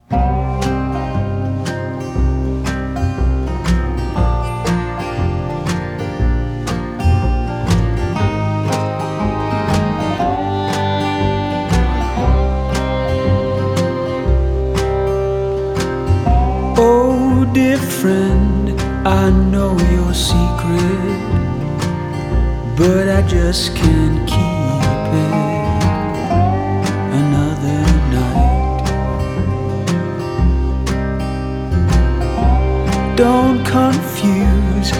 Жанр: Кантри / Альтернатива
# Alternative Country